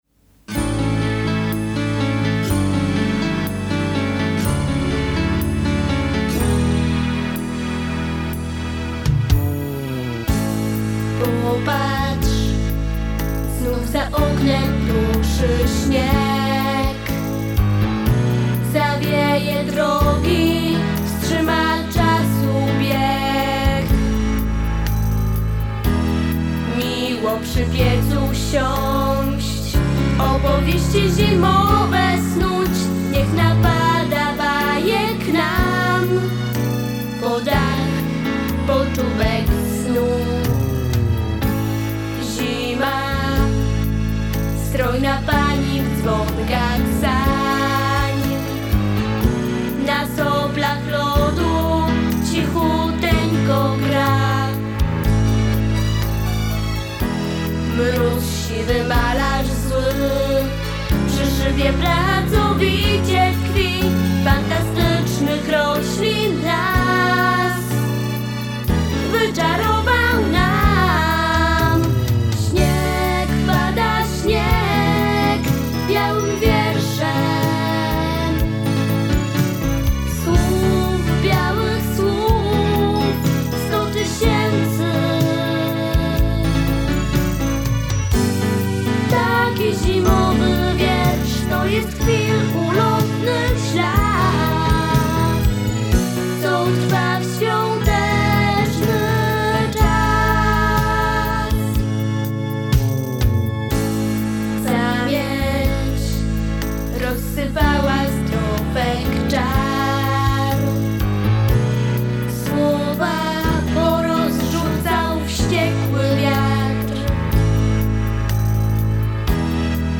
Dodaliśmy archiwalne nagranie piosenki
wokale
nagranie archiwalne z lat 90-tych